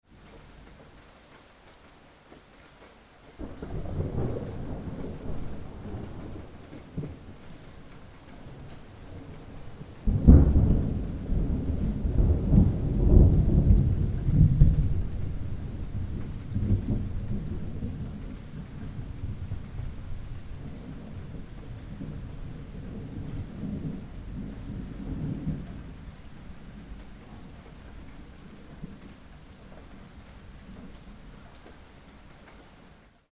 Light Rain With Thunder Rumbling Sound Effect
Nature Sounds / Sound Effects / Thunderstorm Sounds
Light-rain-with-thunder-rumbling-sound-effect.mp3